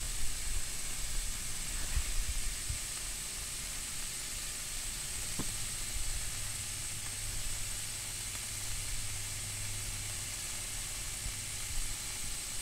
Soothing 765KV
The soothing sound of 765KV towers just outside your bedroom window.